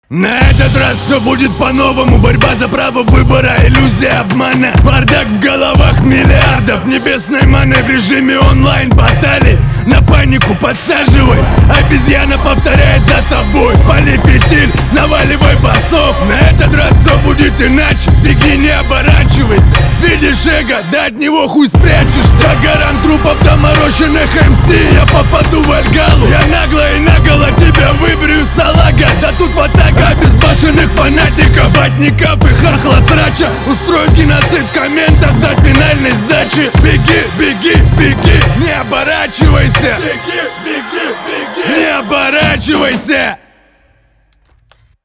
Перетужился